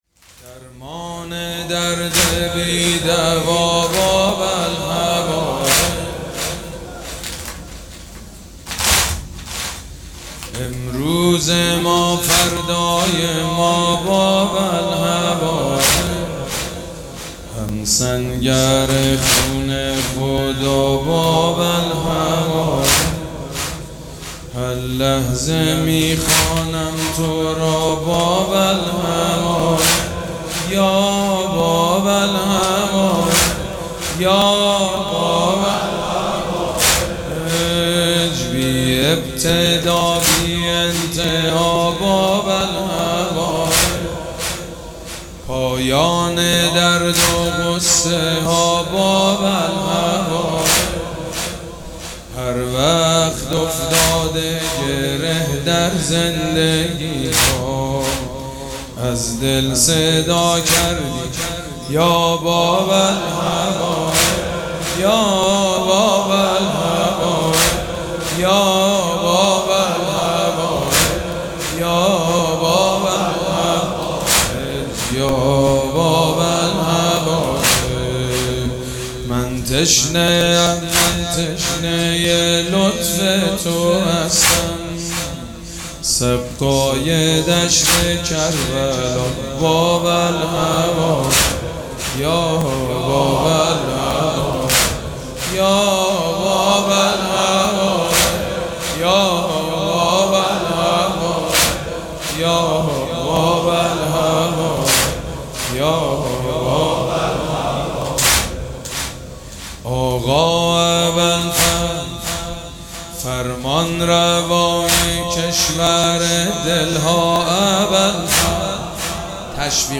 مراسم عزاداری شب نهم محرم الحرام ۱۴۴۷
حاج سید مجید بنی فاطمه